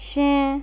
29_I beg your pardon (Female)
29_I-beg-your-pardon-Female.wav